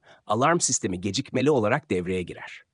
Sesli Anonslar
tts-alarm-tr.mp3